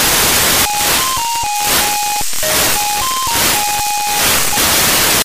The satellite shall transmit at the following frequency: 437.020 MHz (Possible drift for 437.019MHz) FSK G3RUH 9k6 + Melody + CW.